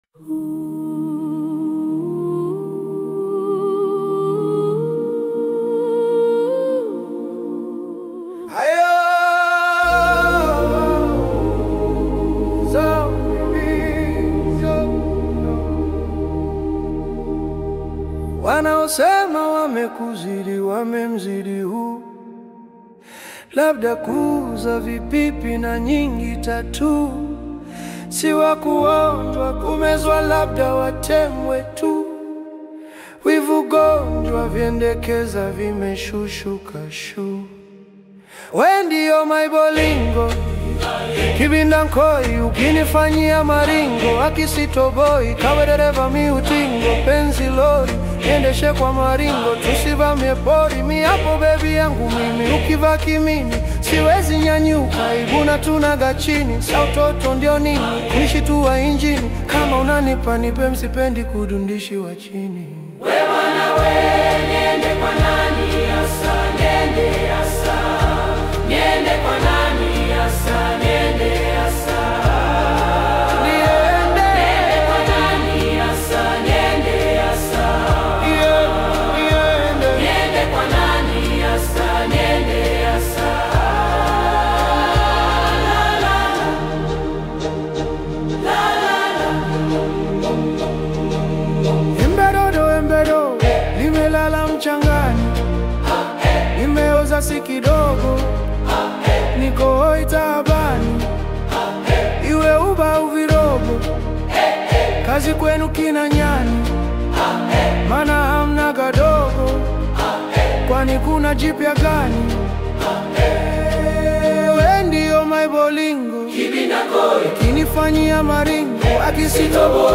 choral reinterpretation